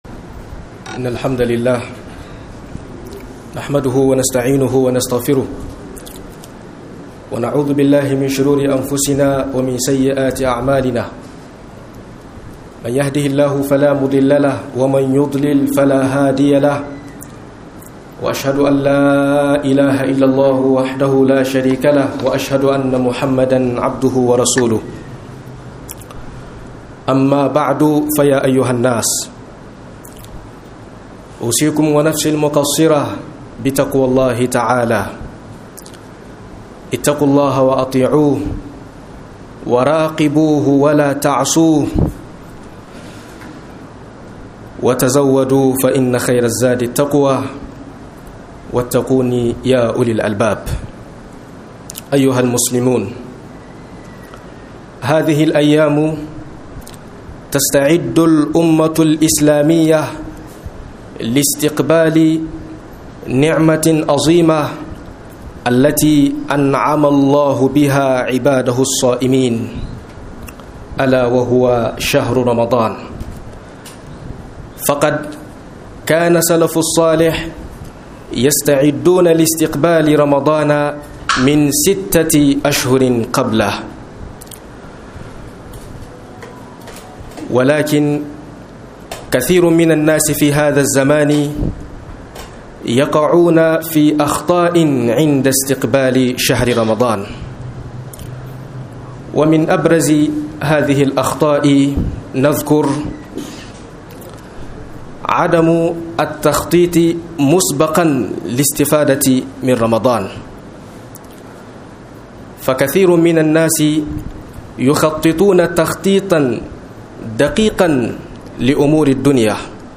04 Kurkuran mutane wajen Tarben Ramadan - MUHADARA